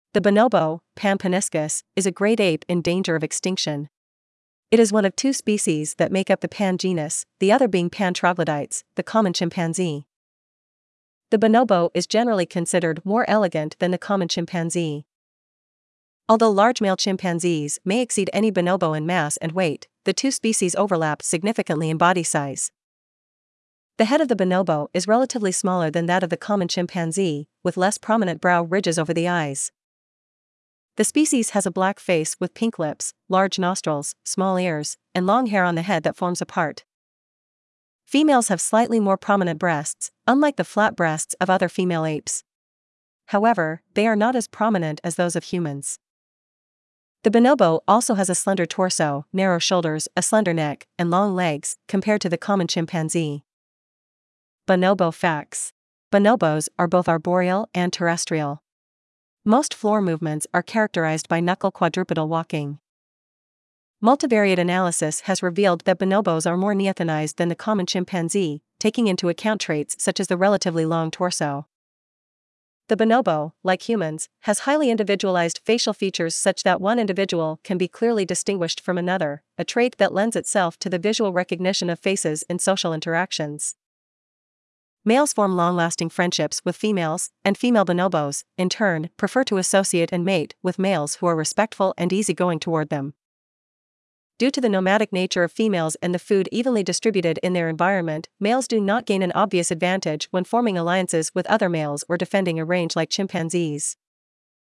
Bonobo
Bonobo.mp3